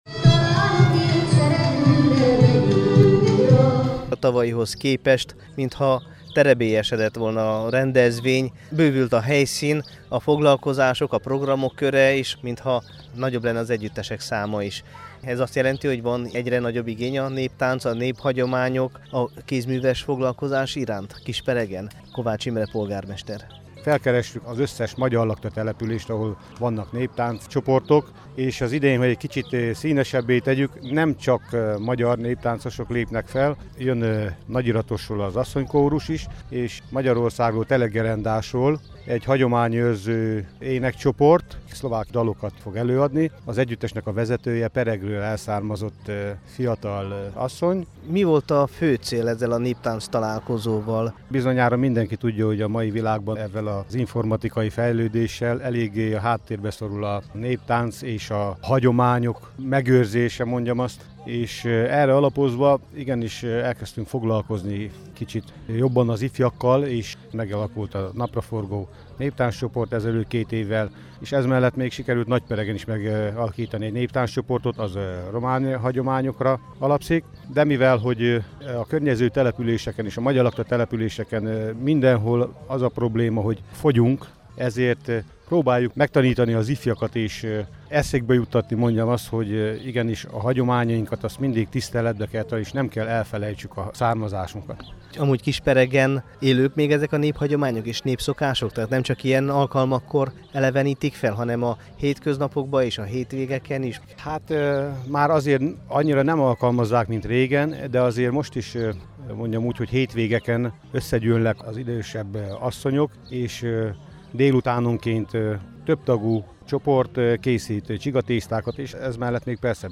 Második alkalommal rendeztek néptánctalálkozót Kisperegen. A szombati program nemcsak a környékbeli magyar népi együtteseknek, hagyományőrző csoportoknak adott alkalmat egymás megismerésére, hanem a népszokásokat, helyi kézművestermékeket is bemutatták a magyar határ menti faluba látogatóknak.
kisperegi_neptanctalalkozo_2016.mp3